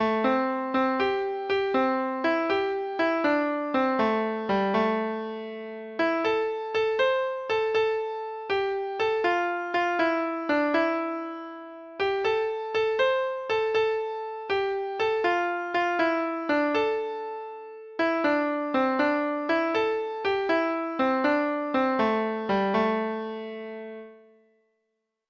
Traditional Trad. Jim Jones Treble Clef Instrument version
Folk Songs from 'Digital Tradition' Letter J Jim Jones
Traditional Music of unknown author.